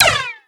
pap_shot_st.LN65.pc.snd.wav